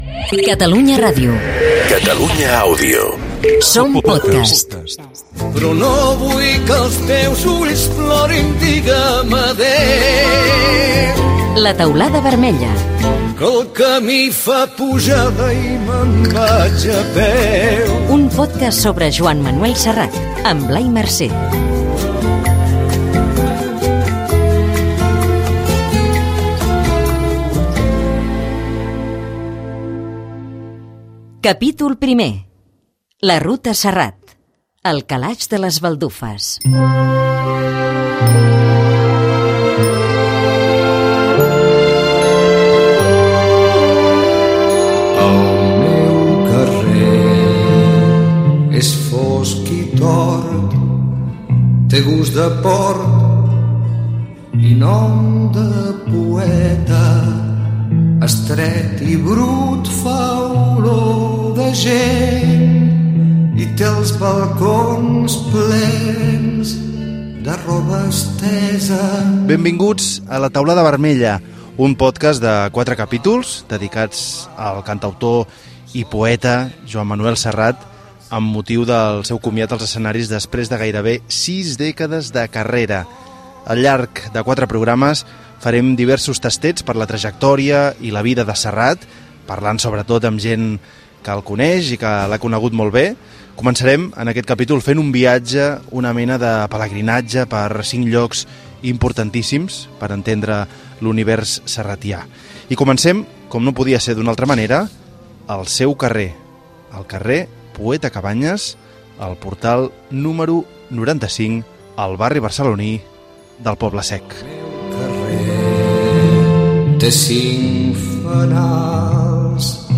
Indicatiu de Catalunya Ràdio-Catalunya Àudio, careta. Capítol 1 "El calaix de les baldufes": el carrer Poeta Cabanyes, del barri del Poble-sec de Barcelona. Intervenció d'alguns veïns de la casa d'infantesa de Joan Manuel Serrat
Entreteniment